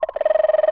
cartoon_electronic_computer_code_10.wav